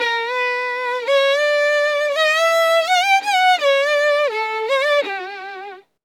声を楽器の音に変換
直感的に歌ったメロディラインから、リアルな楽器パートが作成できます。
▼バイオリンに変換した音声
Ex1_ReSing_After_Violin.mp3